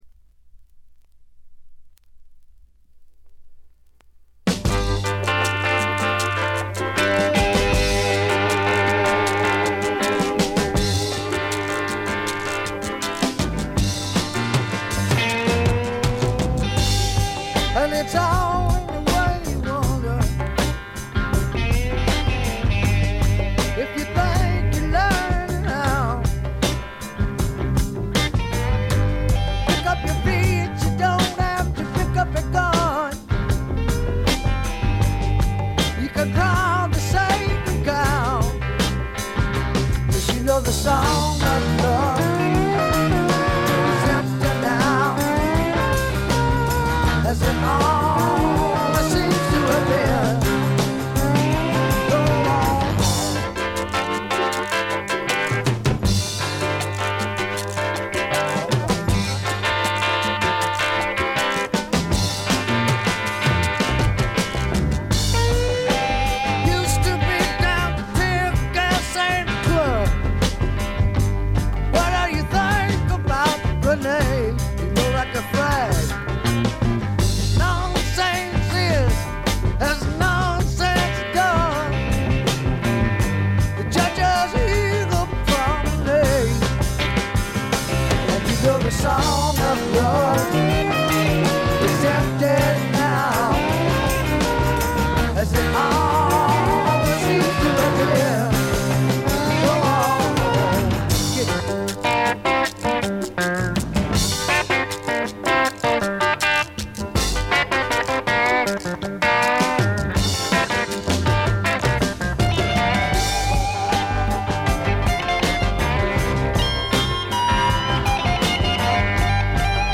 部分試聴ですがわずかなノイズ感のみ。
メドレー形式でロック・ナンバーが繰り広げられていて、エレクトリック・ギターによるロック・サウンドが凝縮されています。
試聴曲は現品からの取り込み音源です。